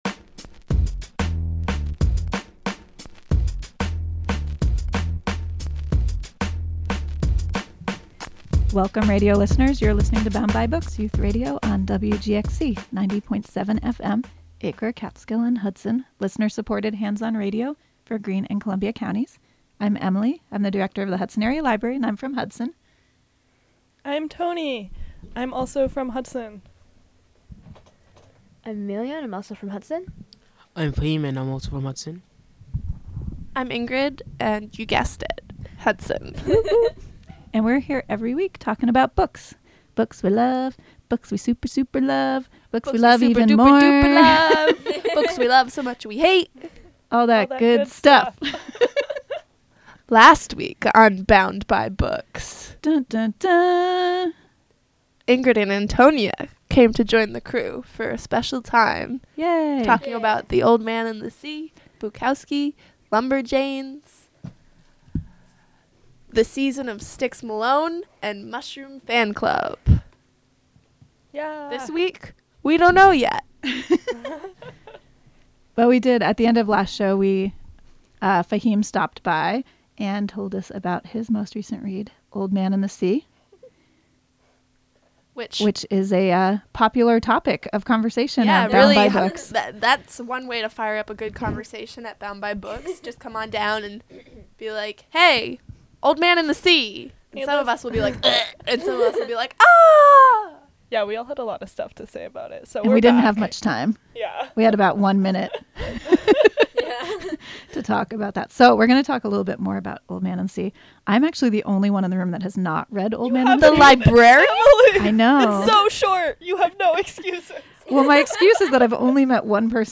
Youth Radio
Recorded in the WGXC Hudson studio, Mon., Dec. 31.